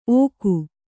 ♪ ōku